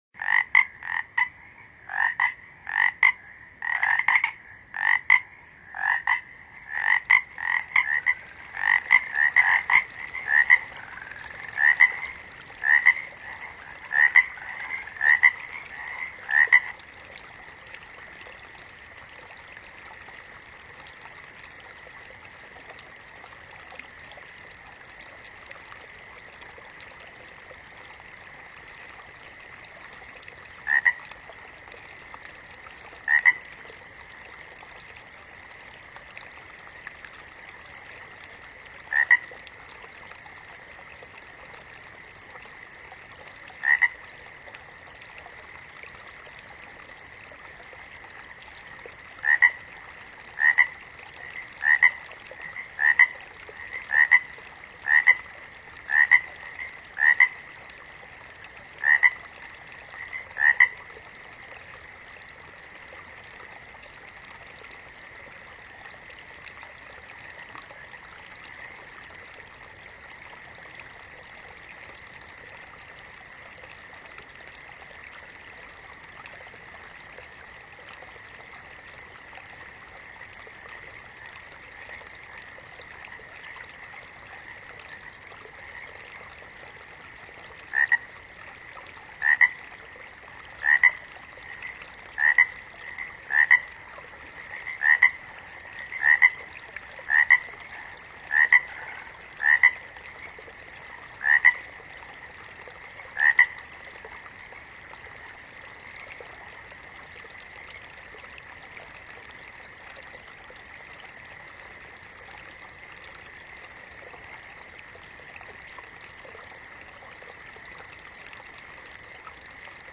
这是大自然天籁的声音，没有掺杂任何的乐器。